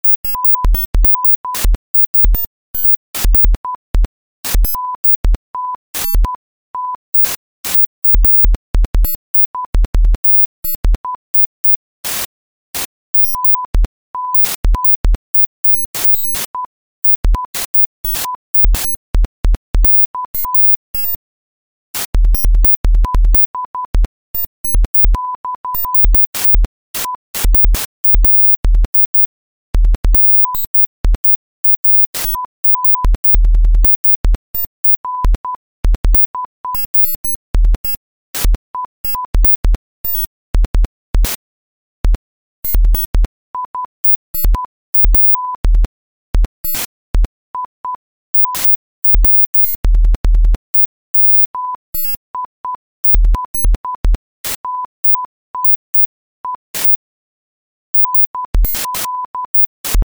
experimental music
uses only sine waves under 20Hz and above 20KHz